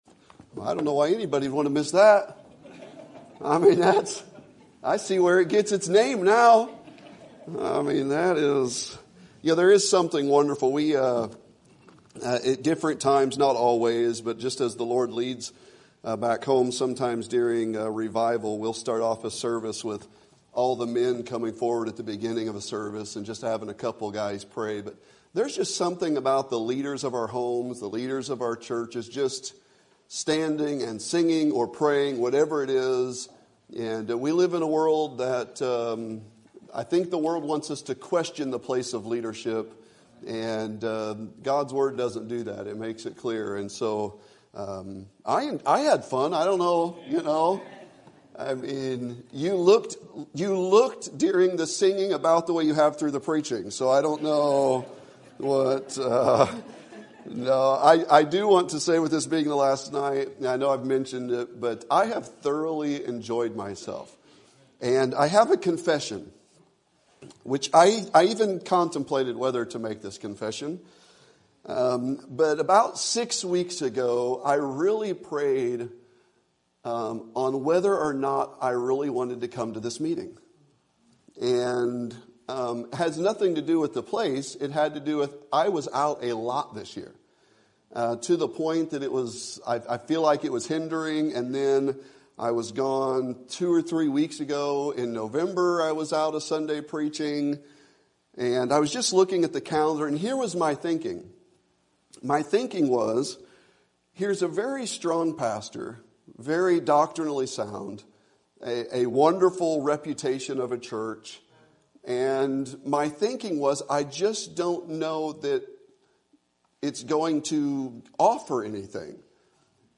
Sermon Date
Sermon Topic: Winter Revival Sermon Type: Special Sermon Audio: Sermon download: Download (28.2 MB) Sermon Tags: 1 Corinthians Love Rejoice Repentance